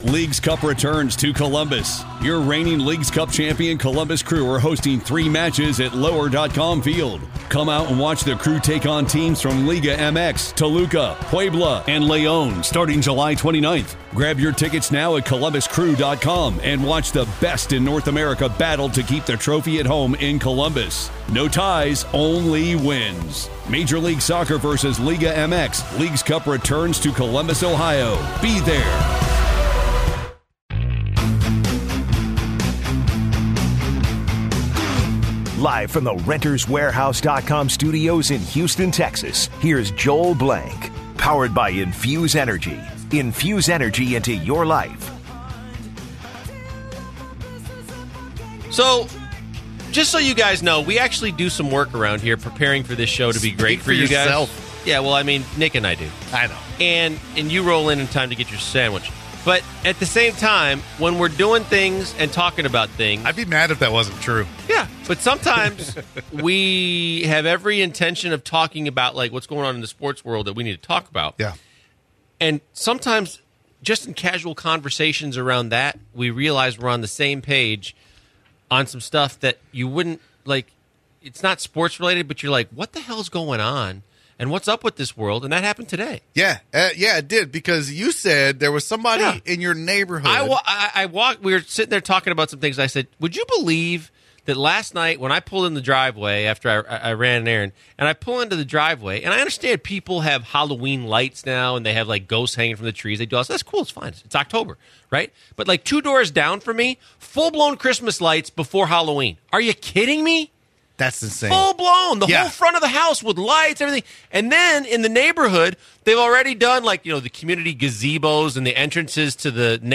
they take calls regarding fantasy as well. We got Space Jam talk as well.